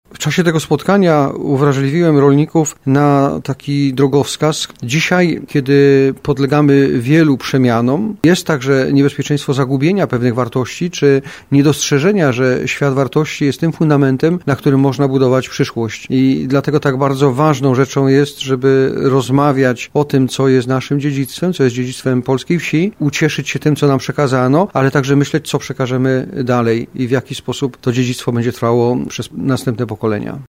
W Wał-Rudzie i Zabawie pod hasłem 'Polska wieś pielgrzymuje do bł. Karoliny” odbyła się pielgrzymka rolników z diecezji tarnowskiej.
Jak mówił do gospodarzy krajowy duszpasterz rolników bp Leszek Leszkiewicz, siłą polskiej wsi jest dziedzictwo i wartości.